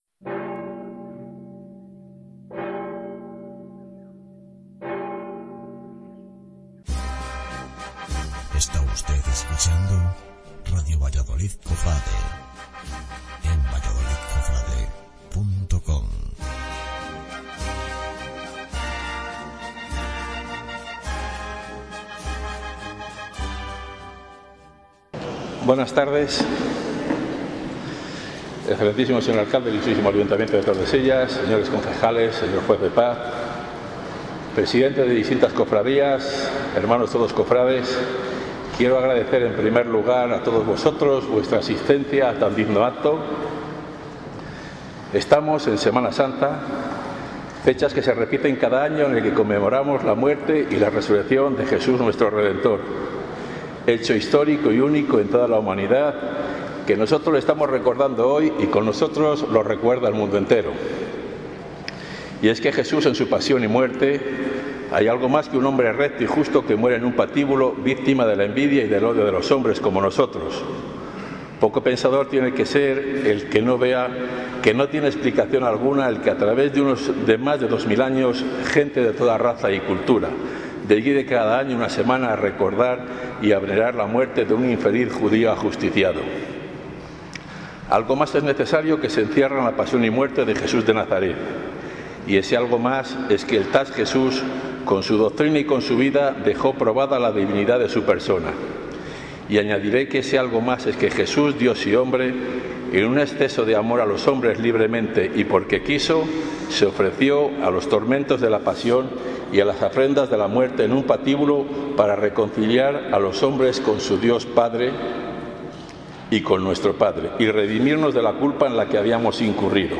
Pregón